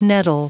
Prononciation du mot : nettle